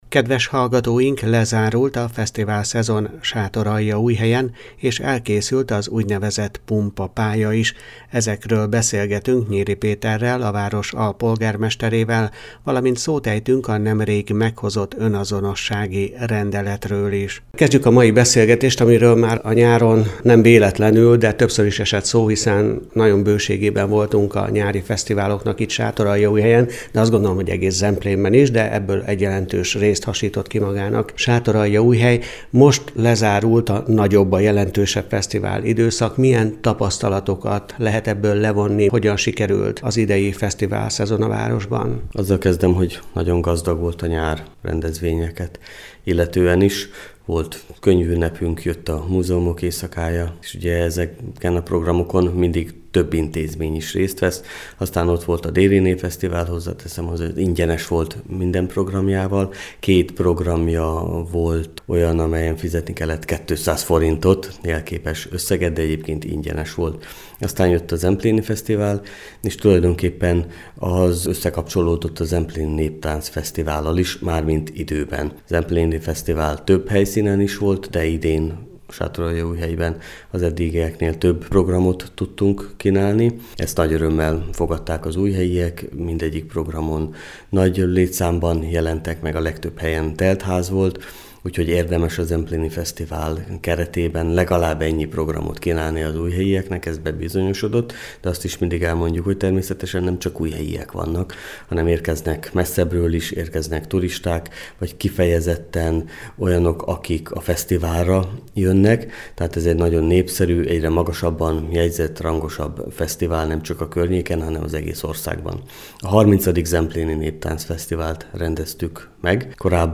Lezárult a fesztiválszezon Sátoraljaújhelyen és elkészült az úgynevezett pumpapálya is. Ezekről beszélgetünk Nyiri Péterrel, a város alpolgármesterével, valamint szót ejtünk a nemrég meghozott önazonossági rendeletről is.